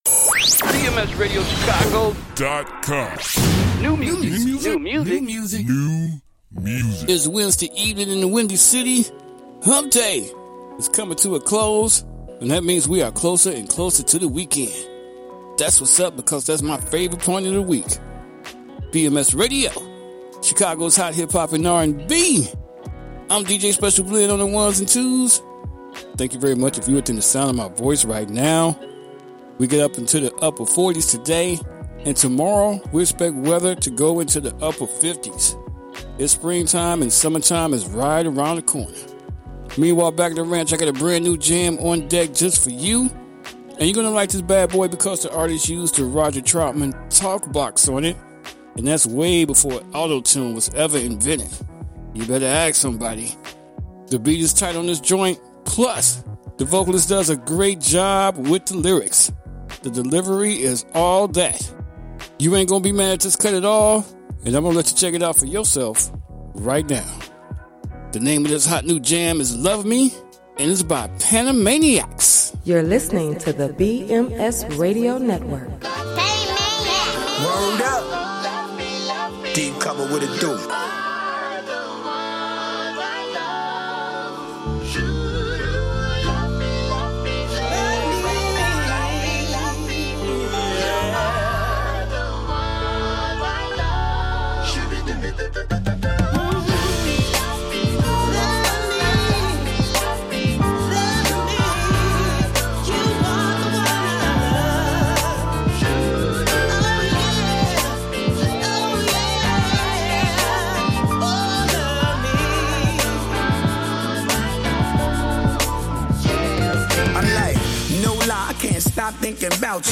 Chicago’s Hot Hip-Hop and R&B.